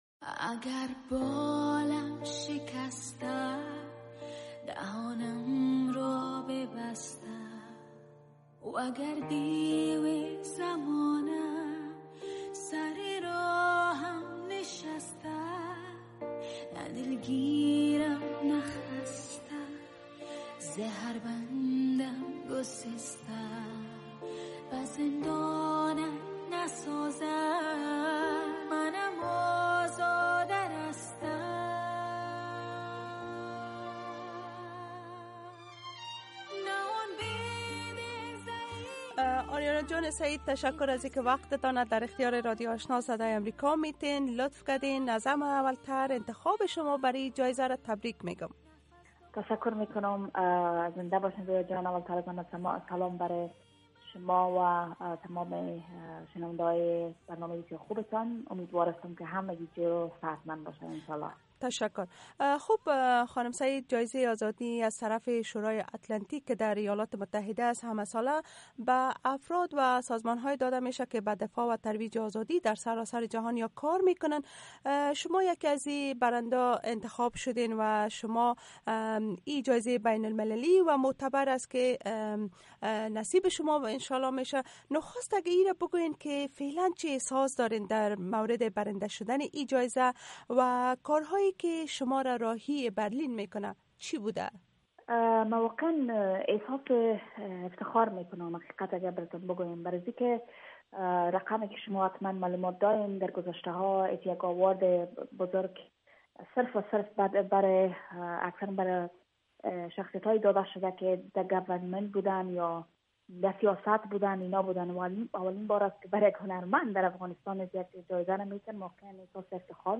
مصاحبۀ آریانا سعید را با رادیو آشنا از اینجا بشنوید: